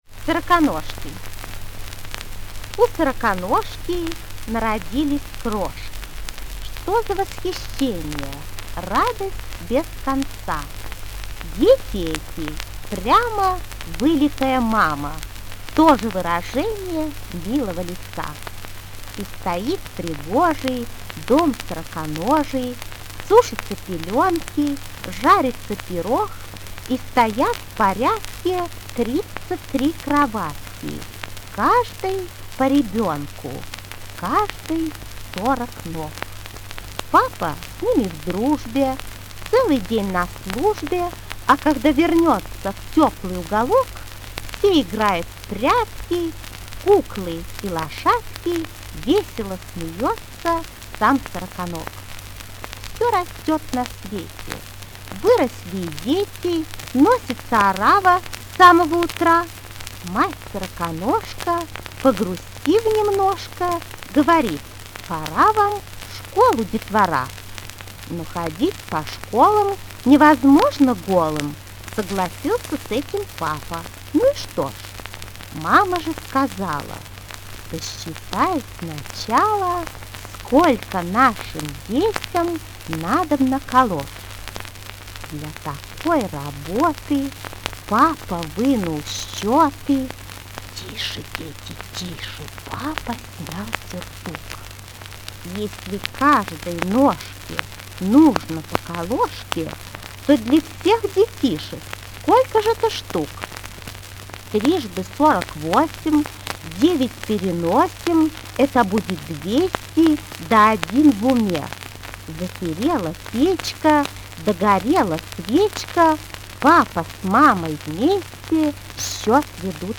2. «Вера Инбер – Сороконожки (читает автор)» /